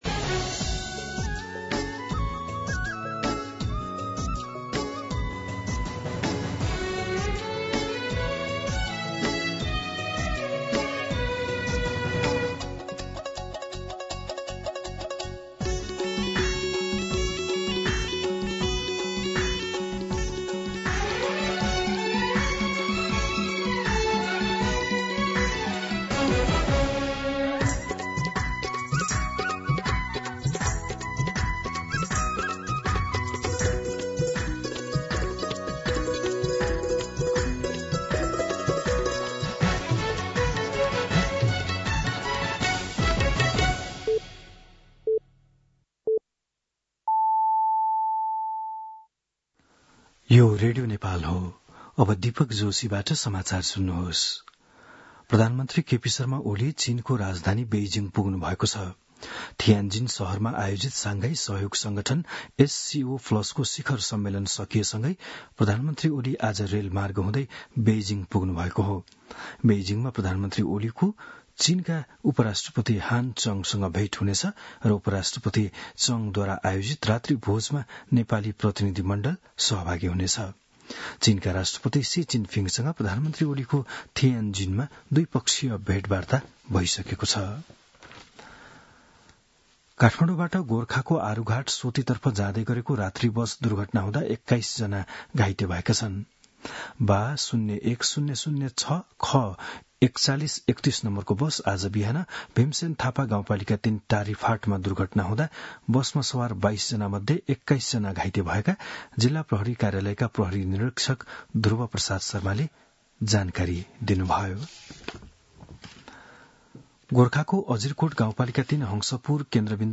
बिहान ११ बजेको नेपाली समाचार : १७ भदौ , २०८२
11am-News.mp3